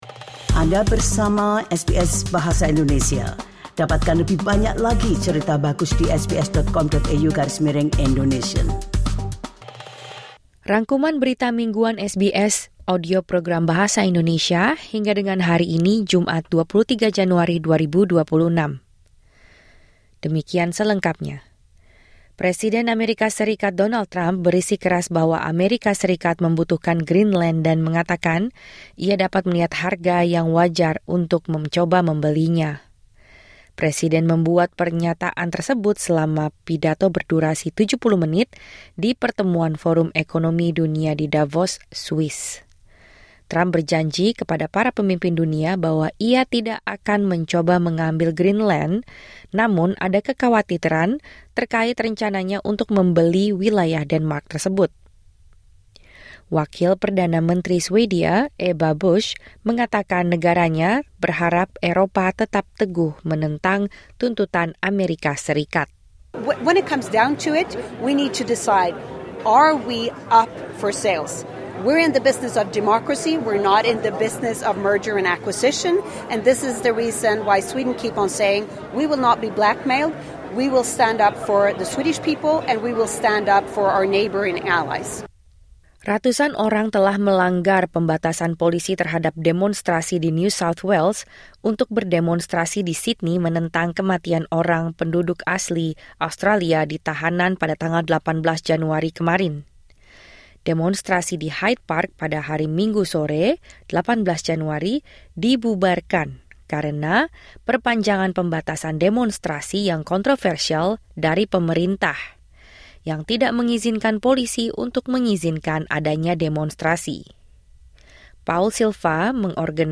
Rangkuman Berita Mingguan SBS Audio Program Bahasa Indonesia - Jumat 23 Januari 2026